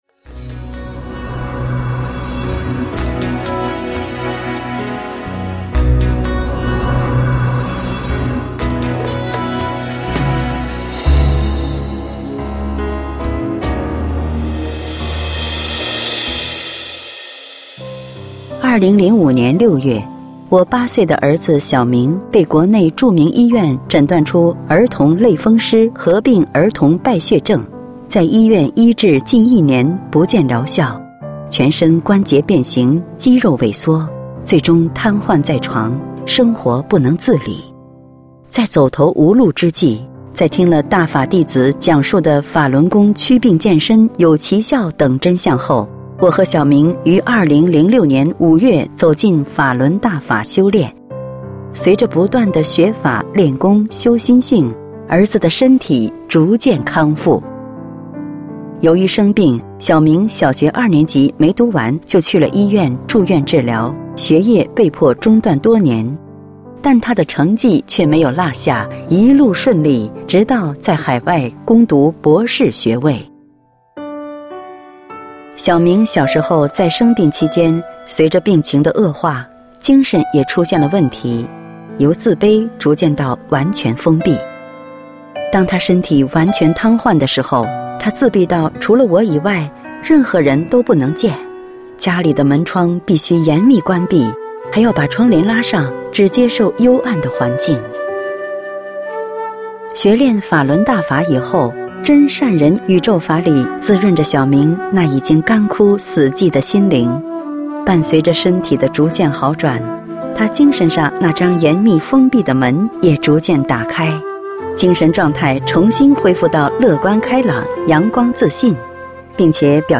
配音